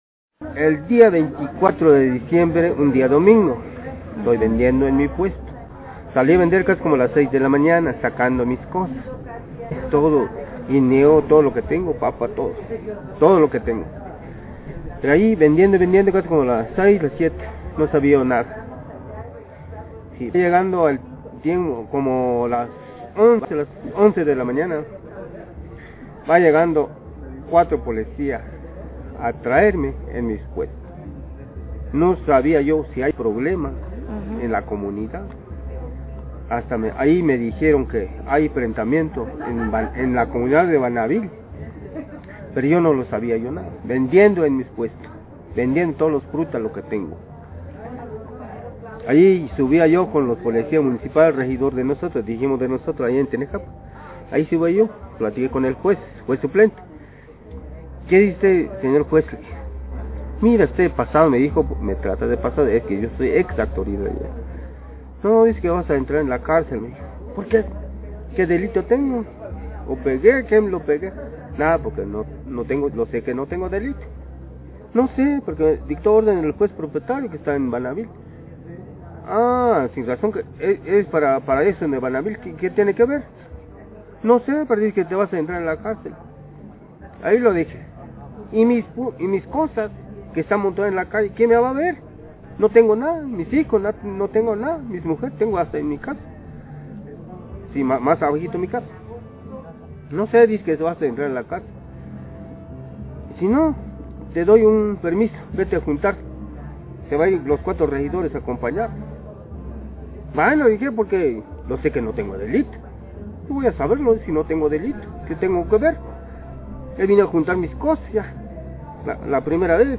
entrevista días antes de su liberación